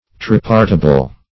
Tripartible \Tri*part"i*ble\, a. Divisible into three parts.